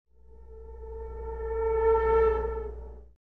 creepy-sound